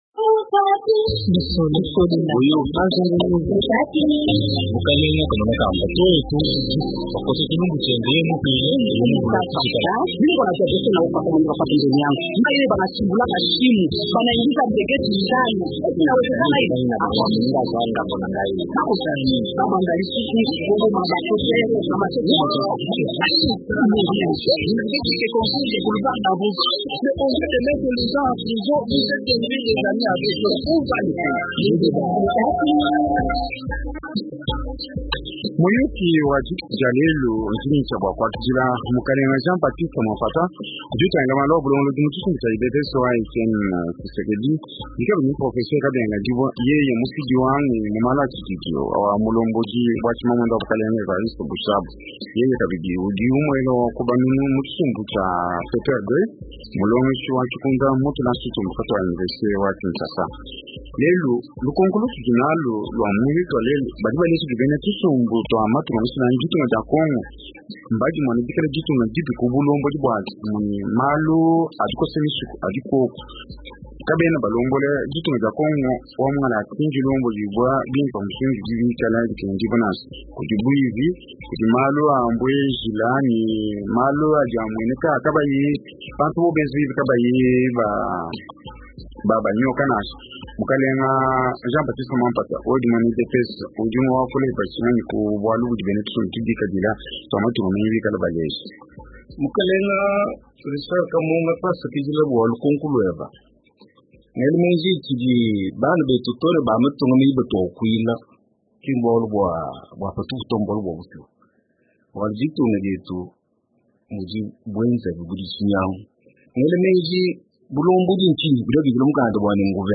L’UDPS et le PPRD débattent de la bonne gouvernance en RDC